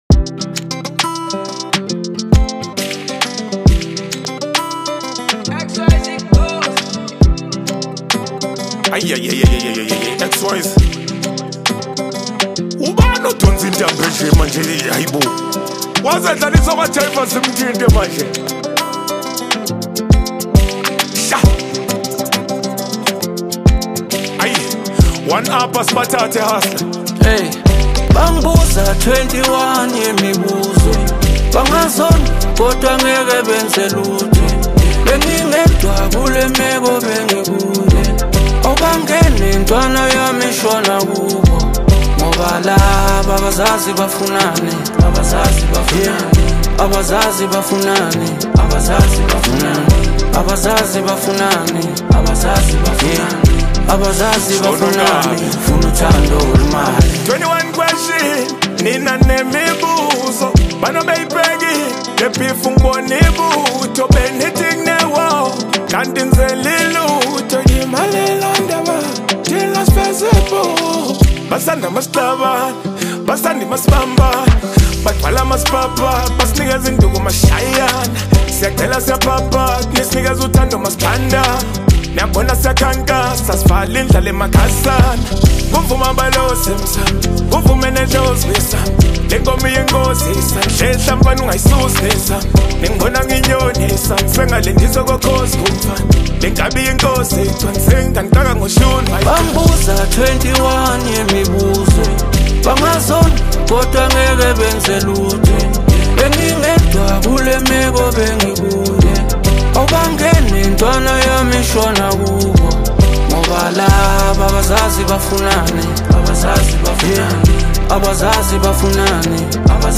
Bring more melody to the Song.